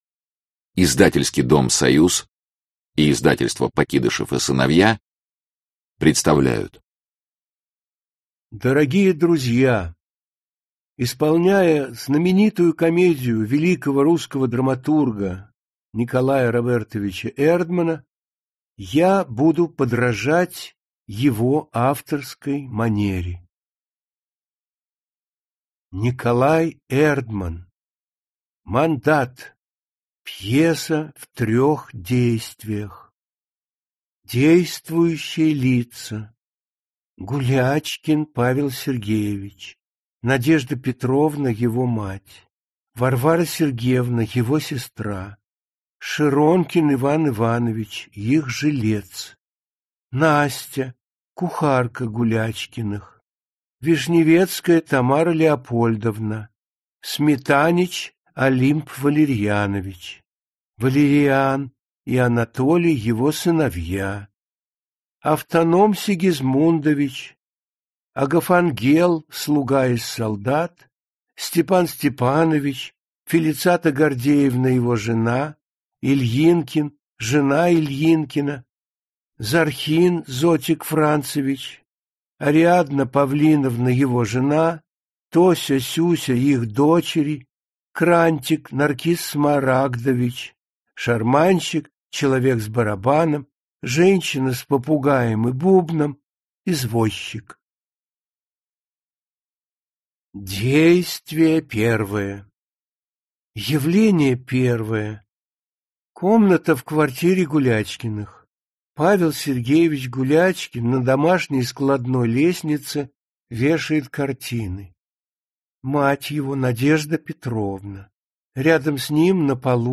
Аудиокнига Мандат | Библиотека аудиокниг
Aудиокнига Мандат Автор Николай Эрдман Читает аудиокнигу Вениамин Смехов.